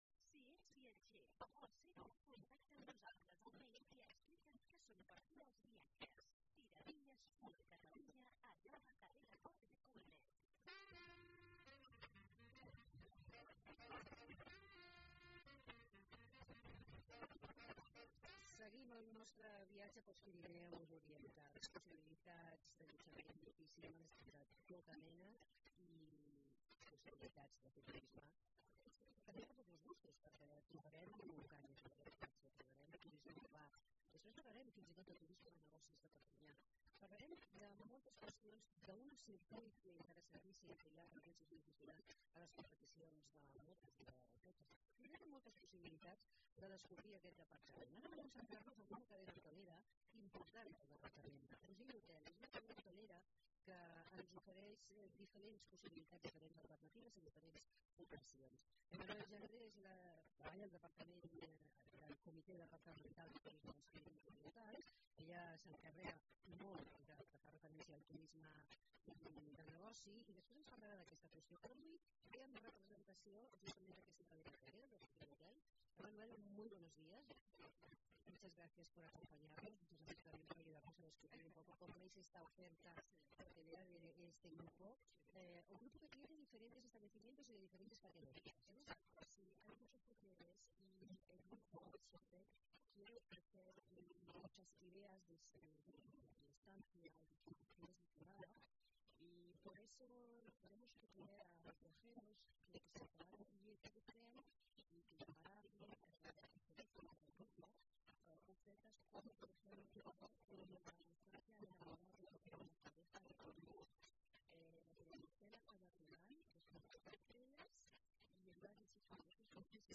Programa especial desde Perpiñan (Primera hora, segunda parte)